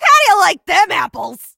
jackie_kill_vo_04.ogg